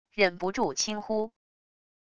忍不住轻呼wav音频